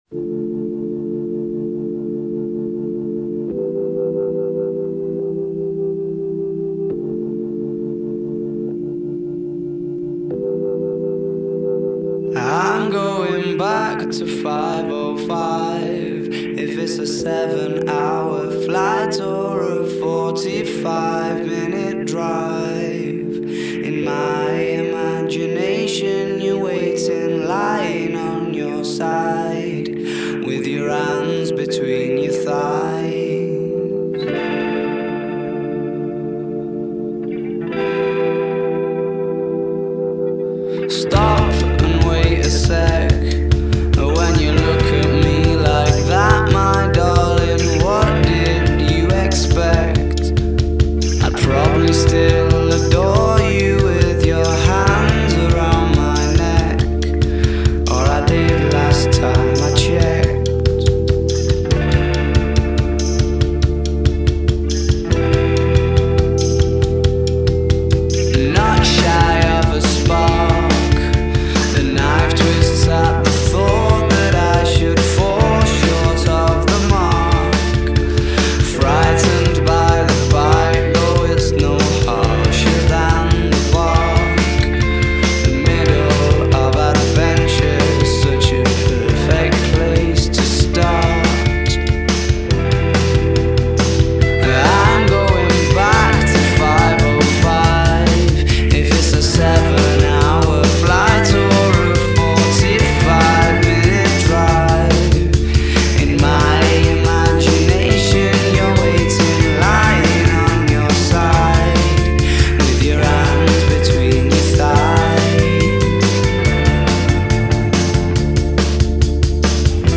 غمگین